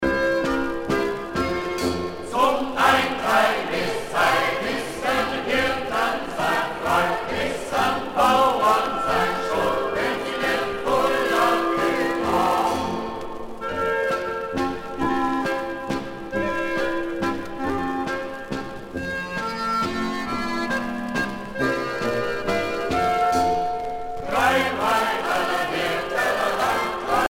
danse : ländler ;
Pièce musicale éditée